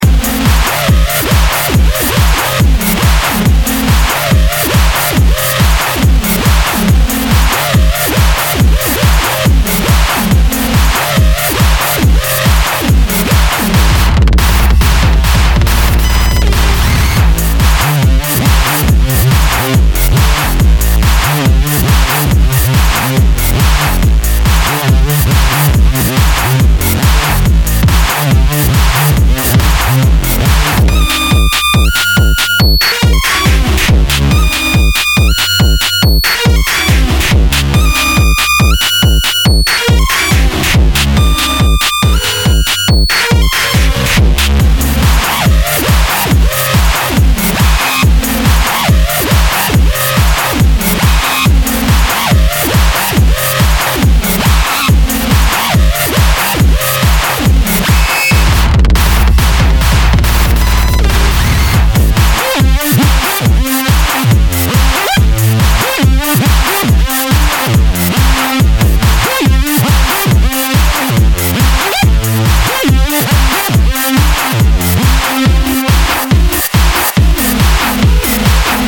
Introducing Mantis307 a fat, mean, and rubbery VST synth that was influenced by the raucous sonic stylings of classic synths like the tb303, mc202, nord3, OSCar and juno60.
::: Mantis really shines when used like a tb303, as a bassline acid monosynth.